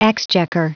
Prononciation du mot exchequer en anglais (fichier audio)
exchequer.wav